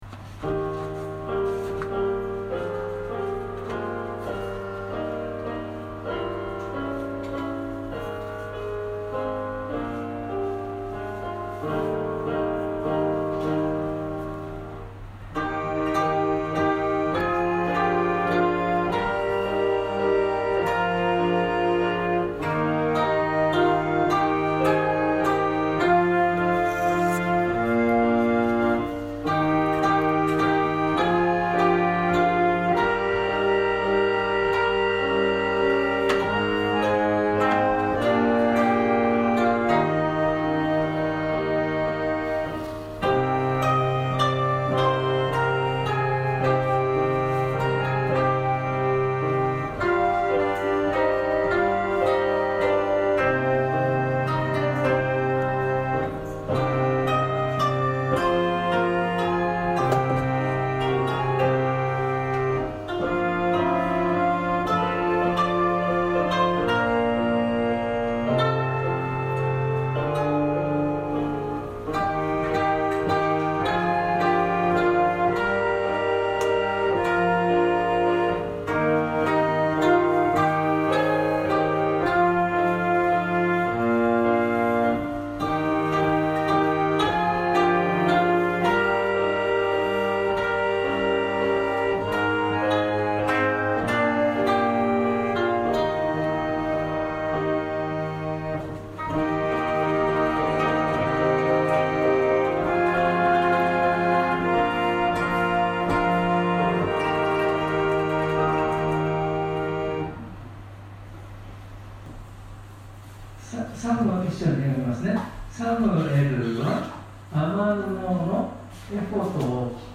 説教アーカイブ。
音声ファイル 礼拝説教を録音した音声ファイルを公開しています。